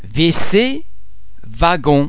The French [v] and [w] are normally pronounced [v] as in the English words victory, savage, veal etc.
w - as in